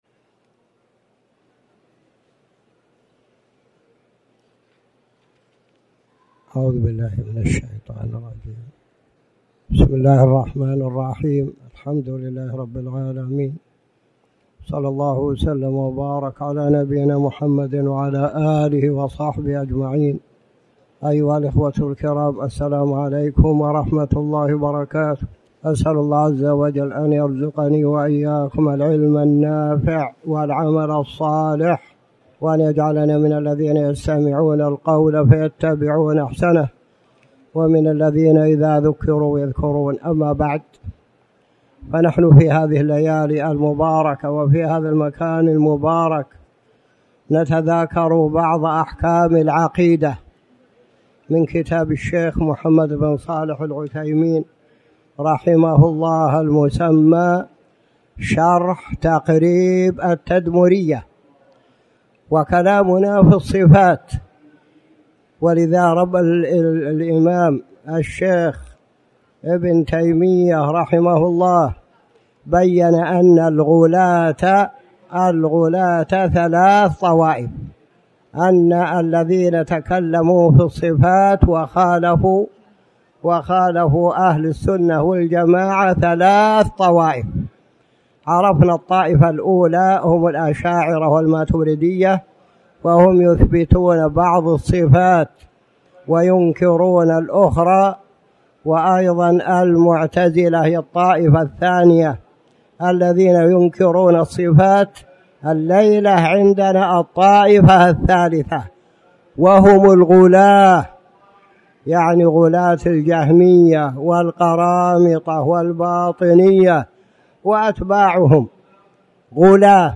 تاريخ النشر ١٢ ذو القعدة ١٤٣٩ هـ المكان: المسجد الحرام الشيخ